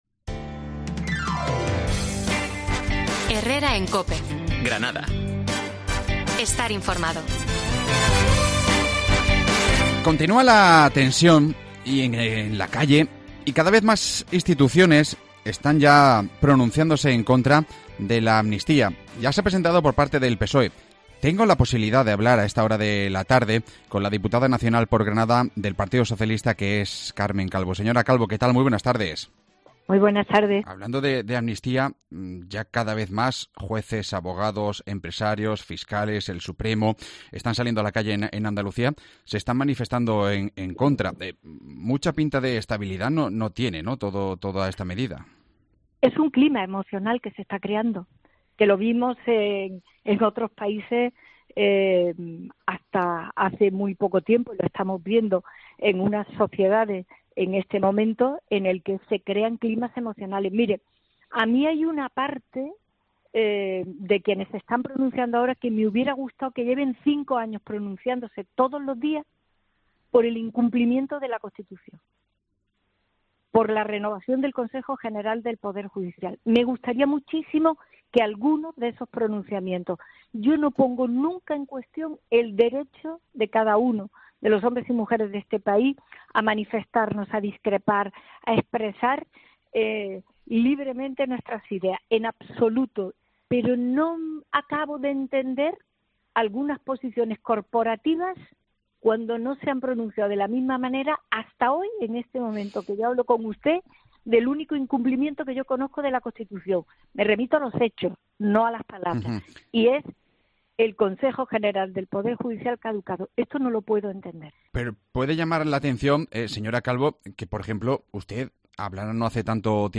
La diputada nacional del PSOE Carmen Calvo ha pasado por los micrófonos de COPE para hablar del ambiente de crispación que se está viviendo en las calles tras el anuncio de la Ley de Amnistía que se une a la desaprobación de jueces, abogados, fiscales y empresarios.